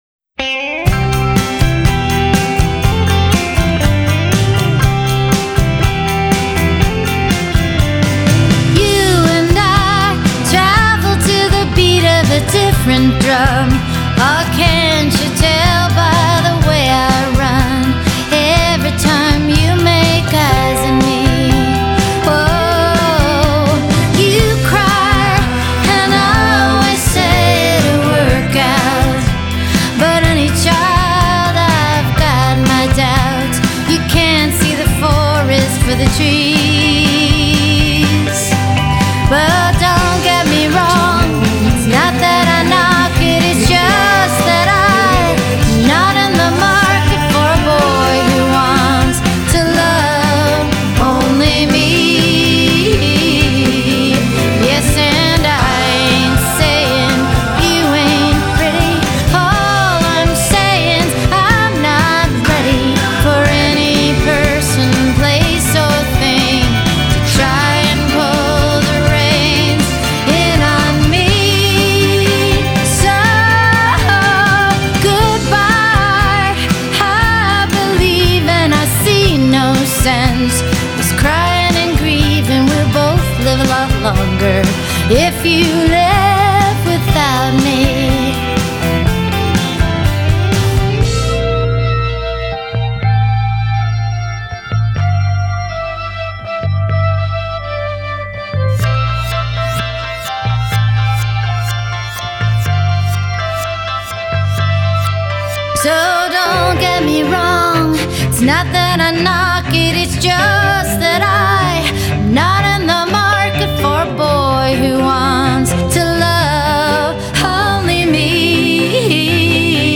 find the requisite jangle and harmonies